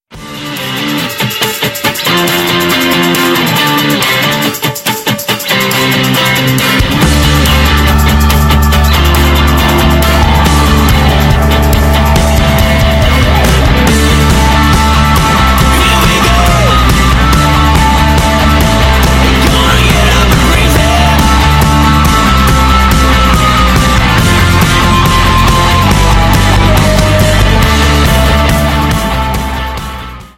energiegeladenen Lobpreis
• Sachgebiet: Praise & Worship